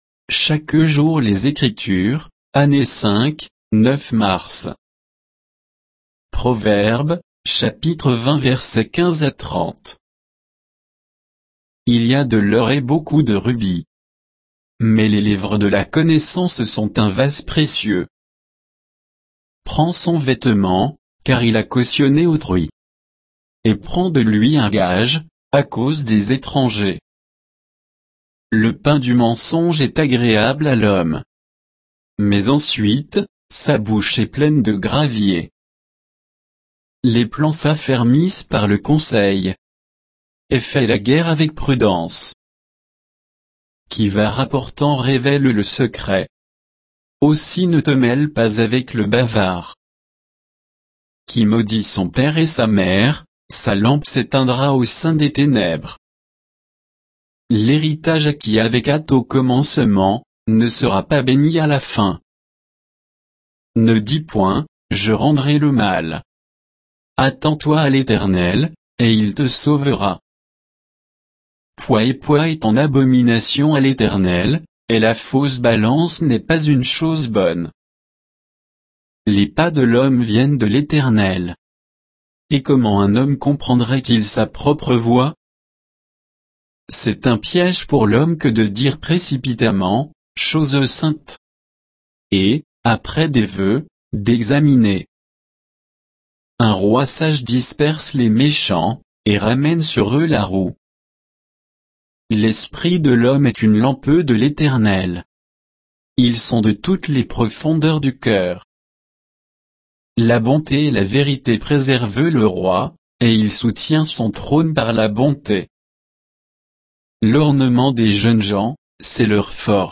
Méditation quoditienne de Chaque jour les Écritures sur Proverbes 20, 15 à 30